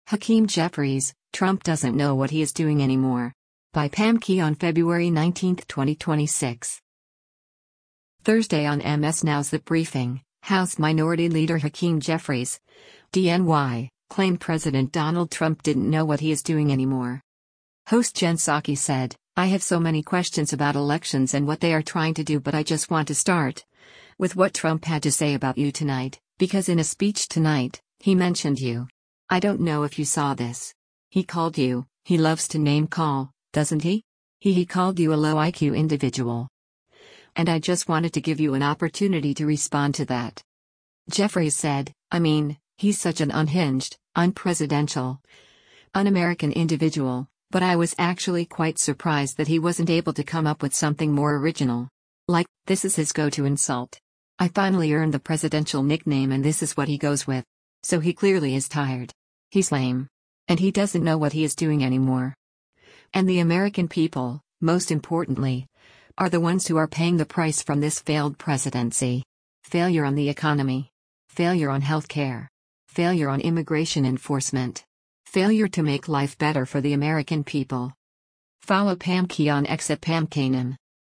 Thursday on MS NOW’s “The Briefing,” House Minority Leader Hakeem Jeffries (D-NY) claimed President Donald Trump didn’t “know what he’s doing anymore.”